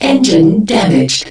1 channel
dam_engine.mp3